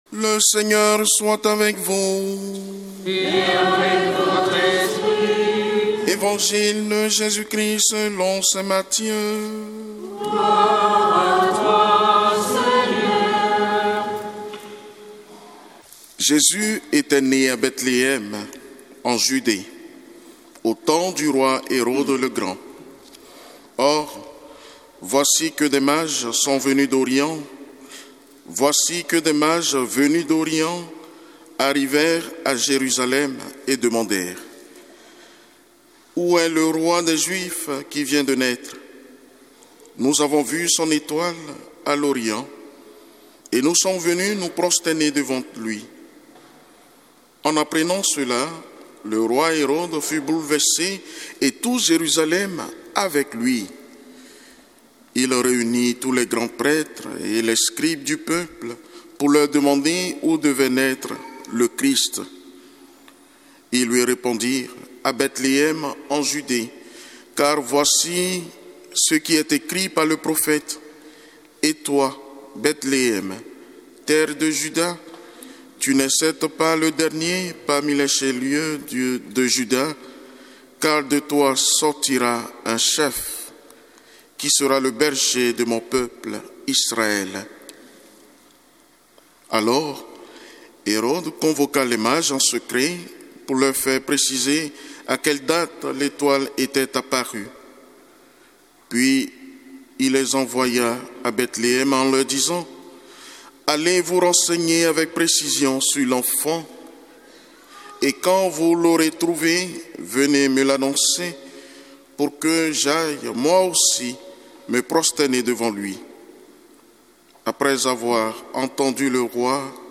Évangile de Jésus Christ selon saint Matthieu avec l'homélie